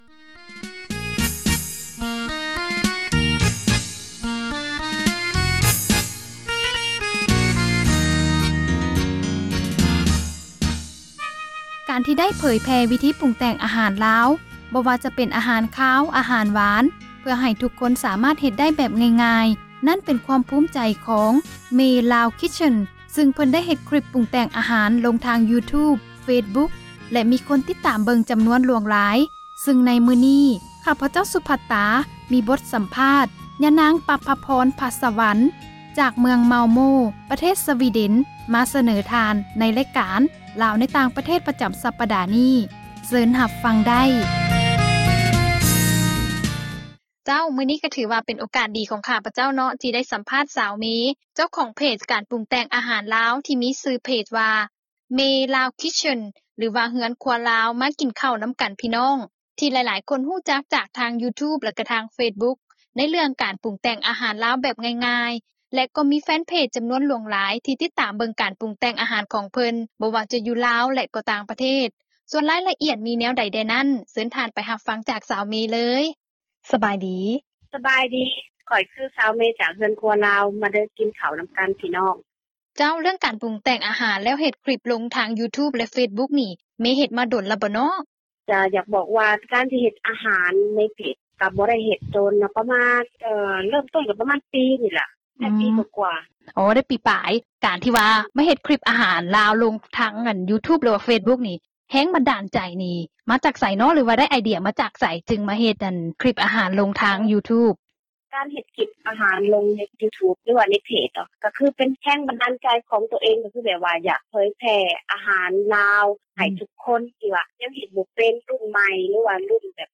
ສັມພາດ